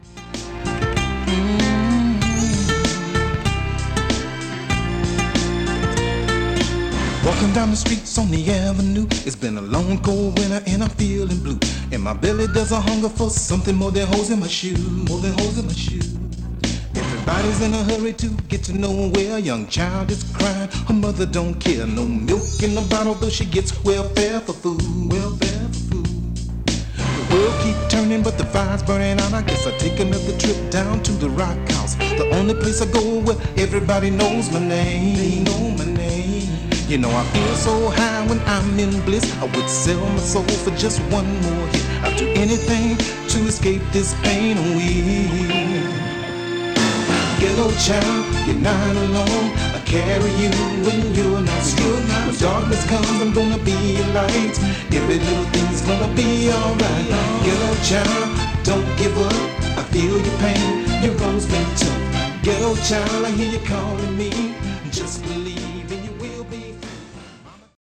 Below ist a test recording made with the GXC-730D and played back by it:
AKAI-GXC-730D-Test-Recording.mp3